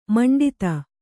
♪ maṇḍita